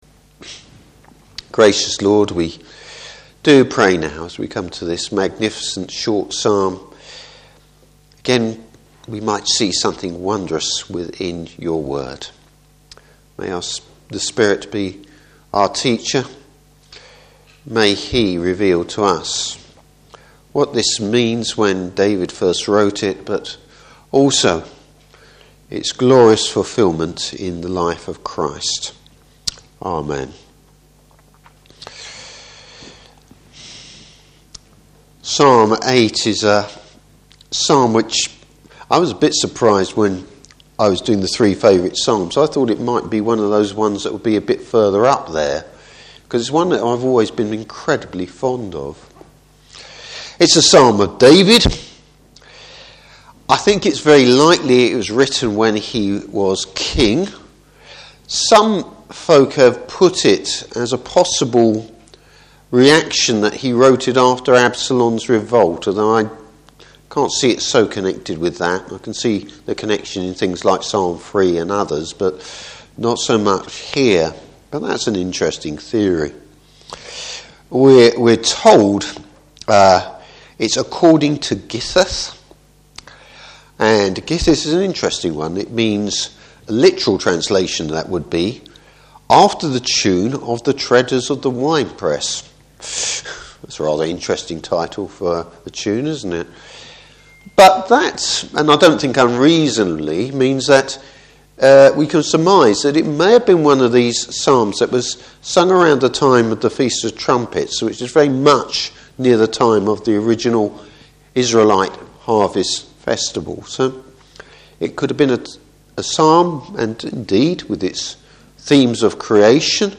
Service Type: Evening Service The God-given nobility of man.